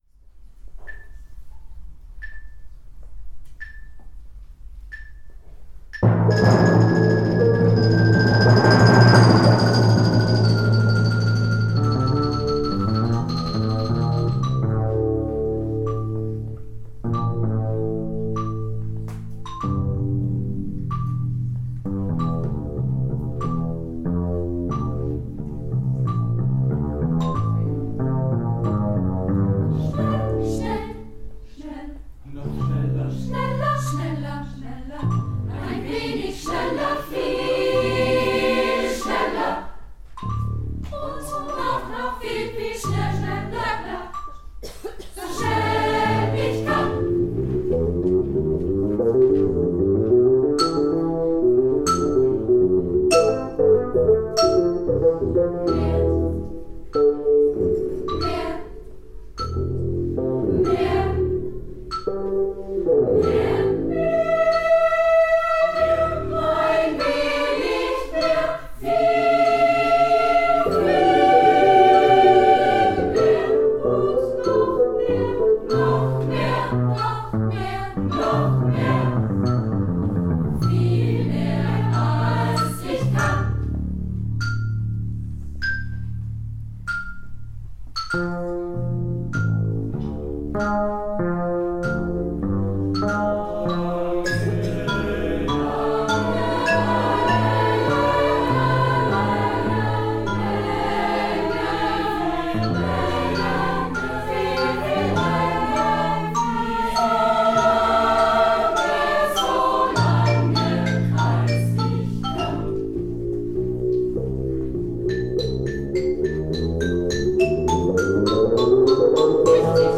Hier finden Sie das Tondokument der Uraufführung.